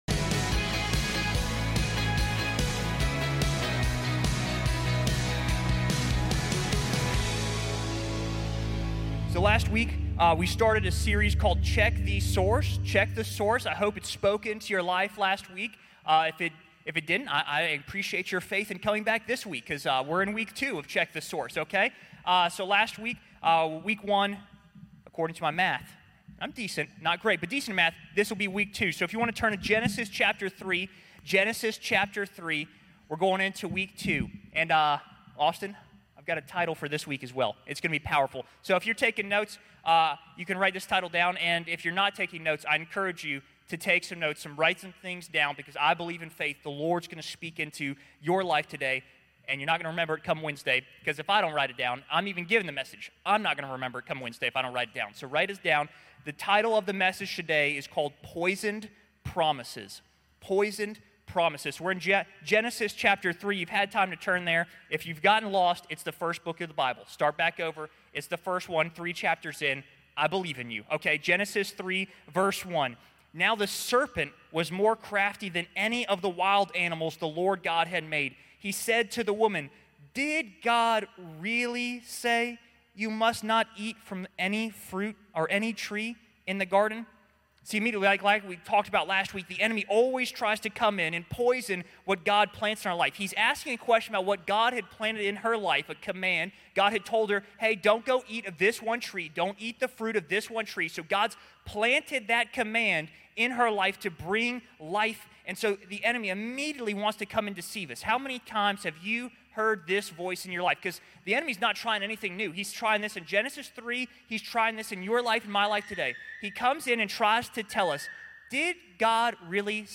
This sermon continues us through our series, "Check the Source."